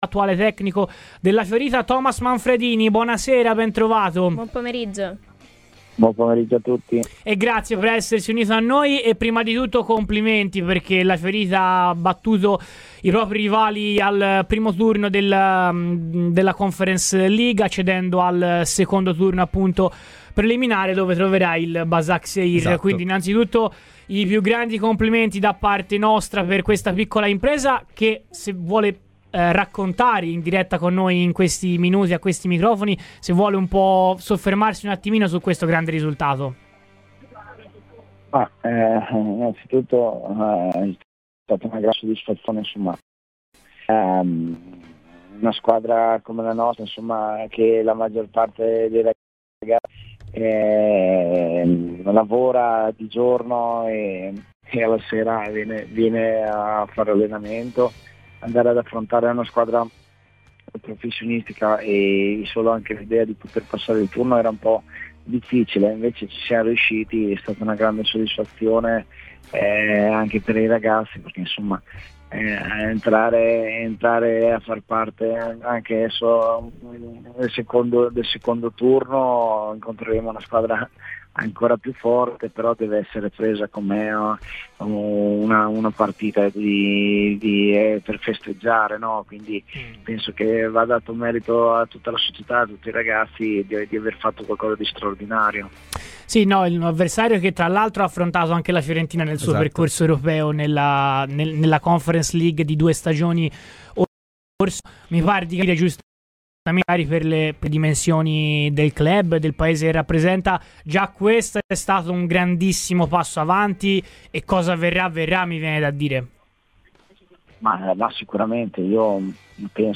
l'ex viola Thomas Manfredini ha parlato oggi a Radio Firenzeviola , durante il 'Viola weekend'